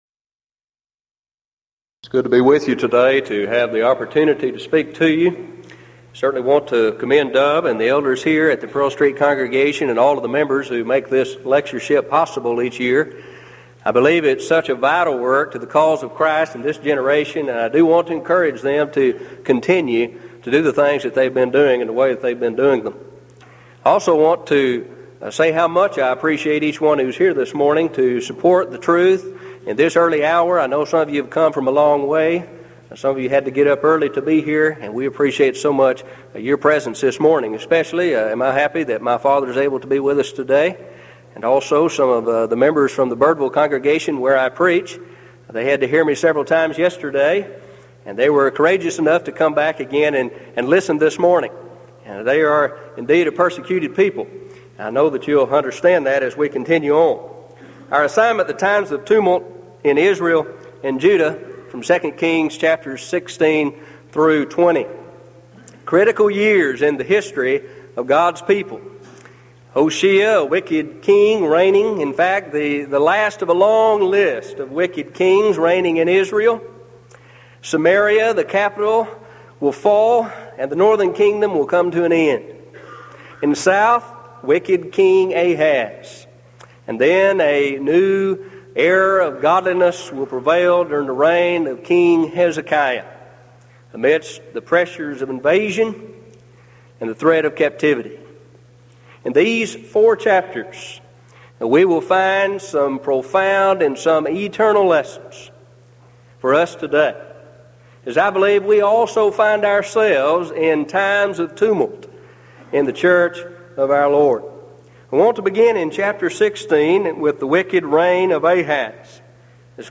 Event: 1993 Denton Lectures Theme/Title: Studies In I & II Kings, I & II Chronicles